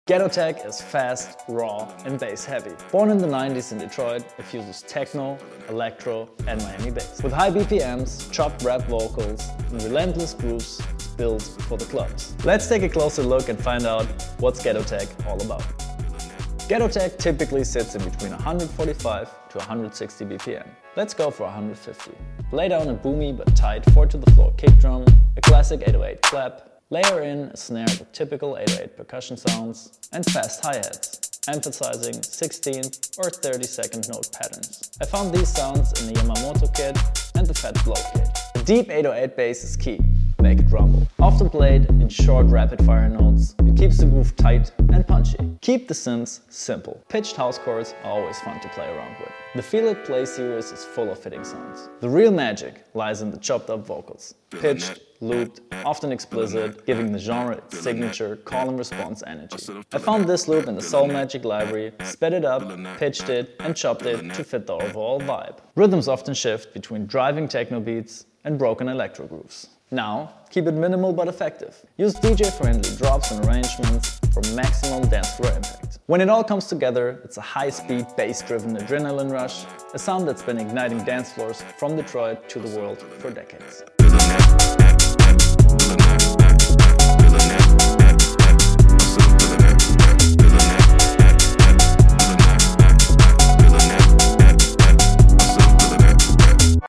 Ghetto Tech: Detroit’s high speed, bass heavy sound effects free download
Ghetto Tech: Detroit’s high-speed, bass-heavy club sound. Fast BPMs, chopped rap vocals & relentless grooves—built for the dancefloor.